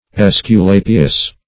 \Es`cu*la"pi*us\